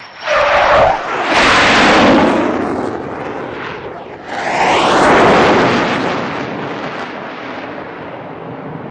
F-104 Starfighter
F-104 Away Only